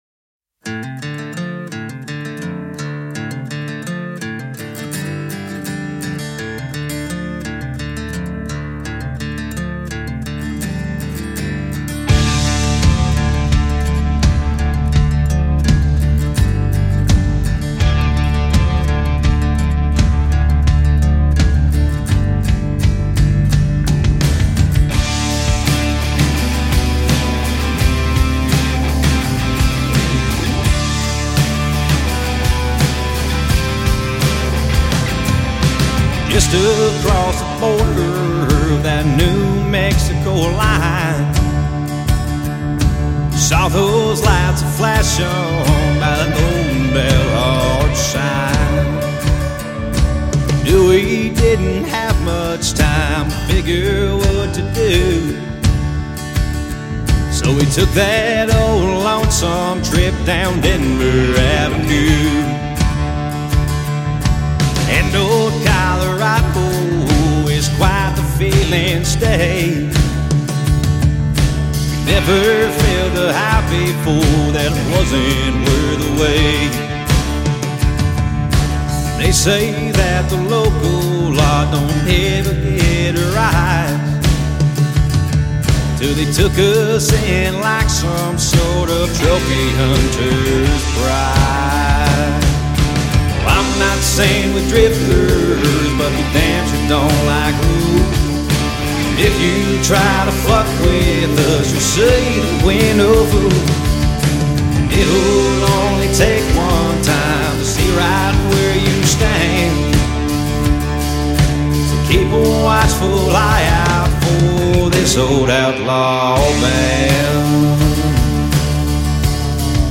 Genre Country